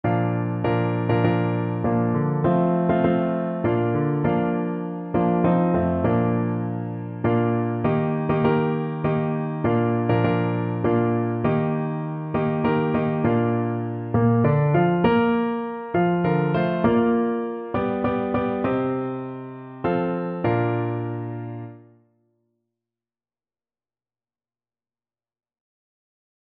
No parts available for this pieces as it is for solo piano.
Traditional Music of unknown author.
Bb major (Sounding Pitch) (View more Bb major Music for Piano )
3/4 (View more 3/4 Music)
Piano  (View more Intermediate Piano Music)
Christian (View more Christian Piano Music)
glorious_gates_PNO.mp3